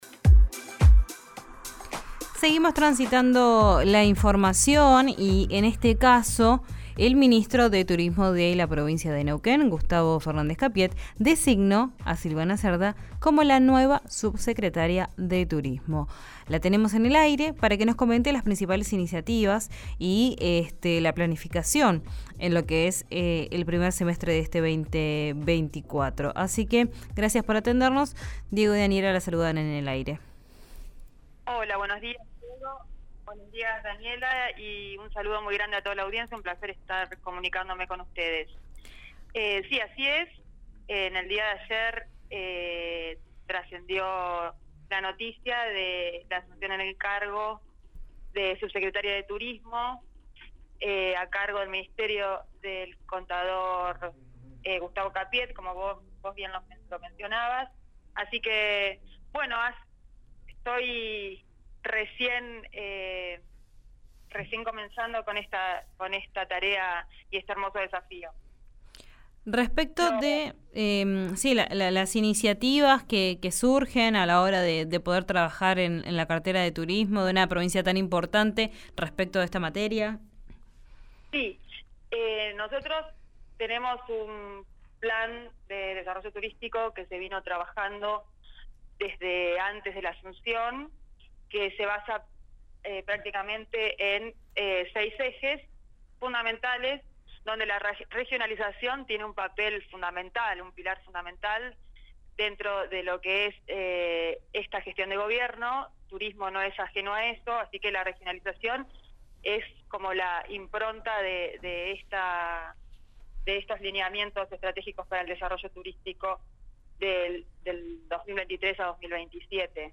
La nueva subsecretaria de Turismo de Neuquén habló con RÍO NEGRO RADIO. Dio detalles de las proyecciones en su cartera.